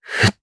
Esker-Vox_Casting1_jp_b.wav